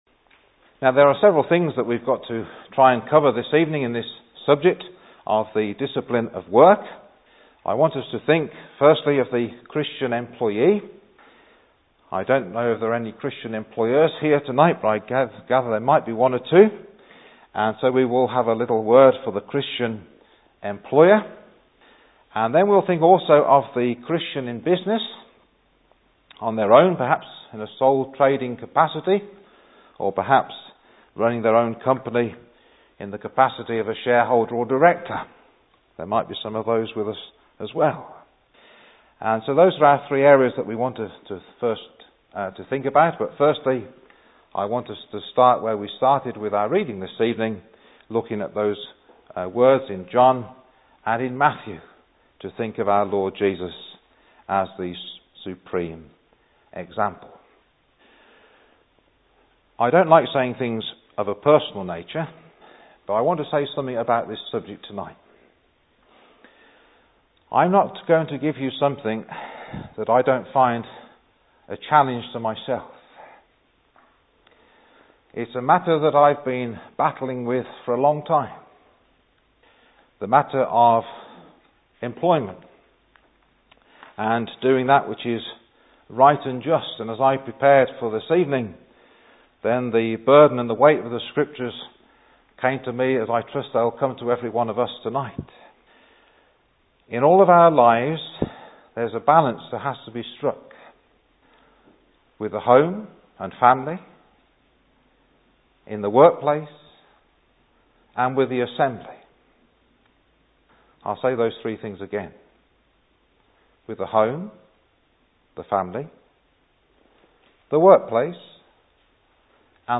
He highlights the responsibilities of the Christian in the workplace and in the duties of the servant-master sphere (Message preached 9th June 2011)